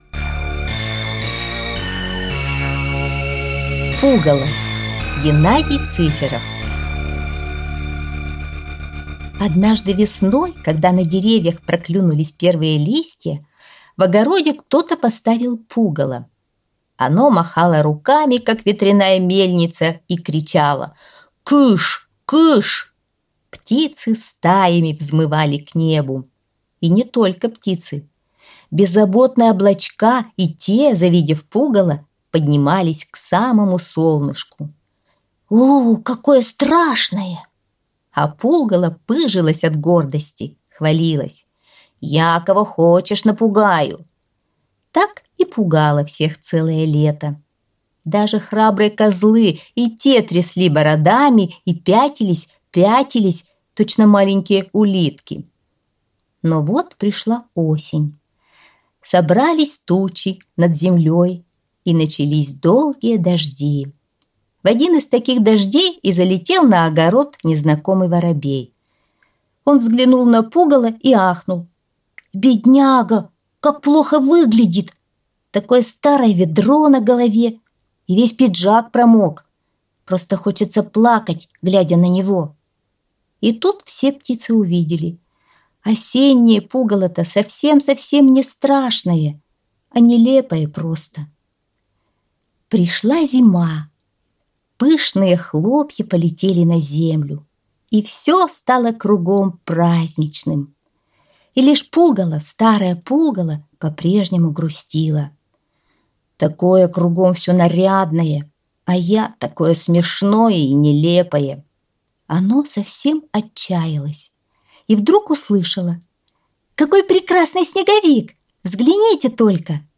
Аудиосказка «Пугало»